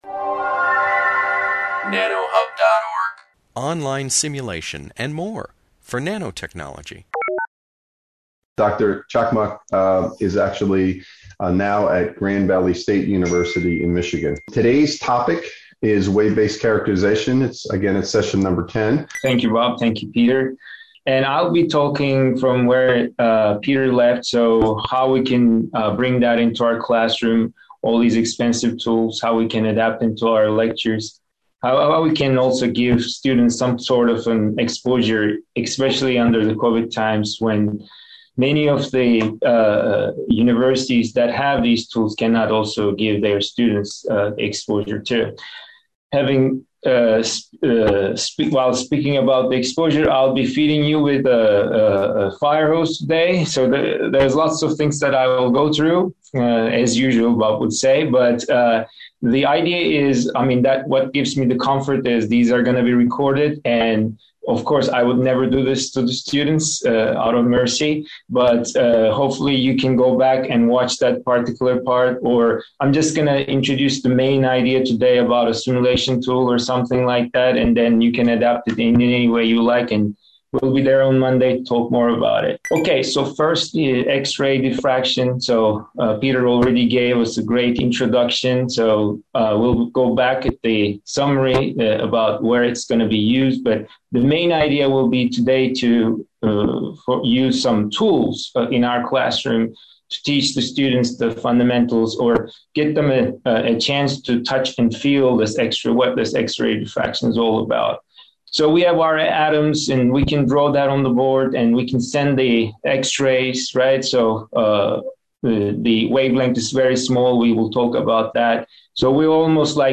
This webinar, published by the Nanotechnology Applications and Career Knowledge Support (NACK) Center at Pennsylvania State University, is the second of a two-part lecture on X-ray diffraction (XRD) for characterization.